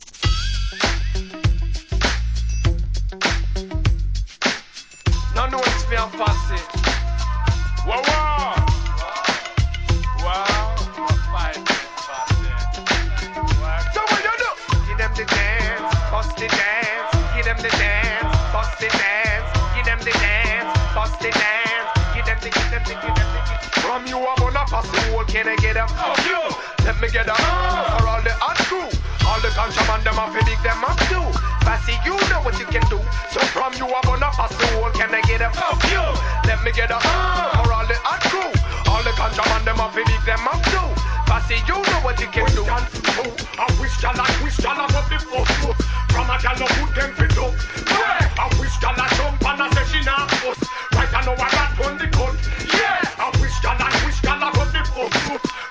HIP HOP/R&B
REMIX、MUSH UP物!!